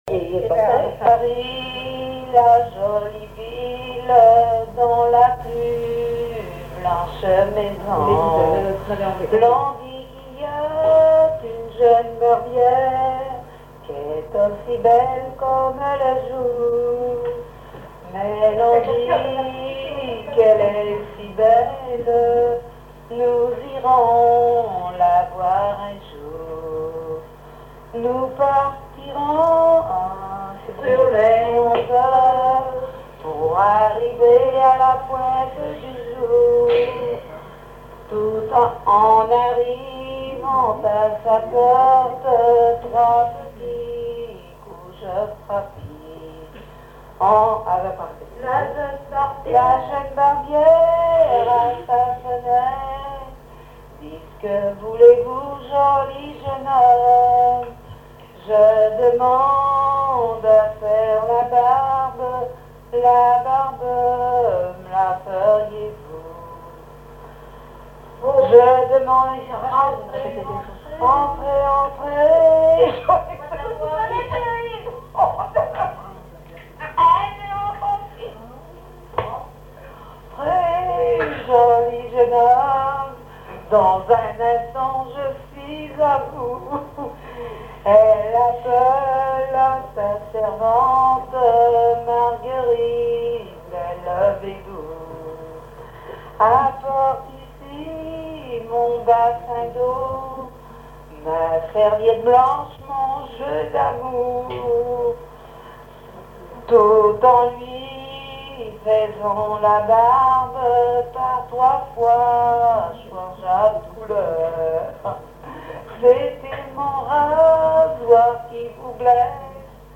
collecte en Vendée
chansons traditionnelles et commentaires
Pièce musicale inédite